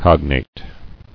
[cog·nate]